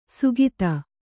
♪ sugita